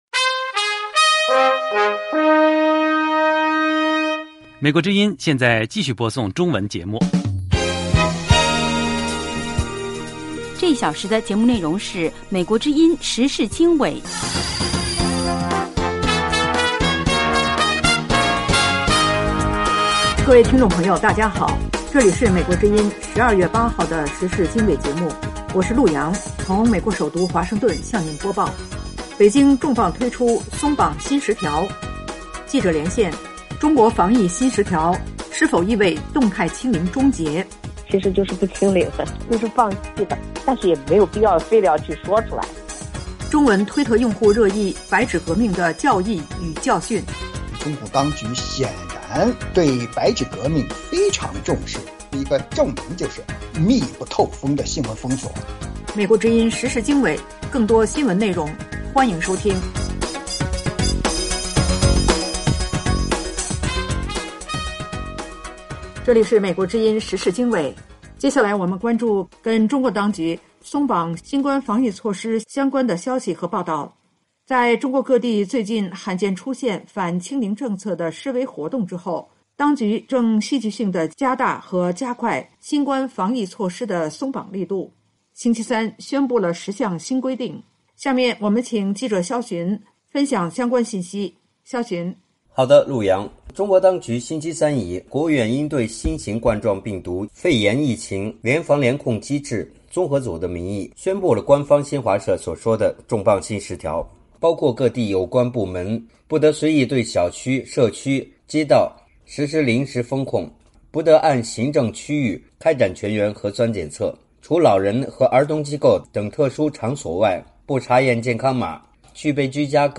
时事经纬(2022年12月8日)：1/动态清零政策大转弯 北京“重磅”推出松绑新十条。2/记者连线：中国防疫“新十条”轻症居家隔离 “动态清零”终结？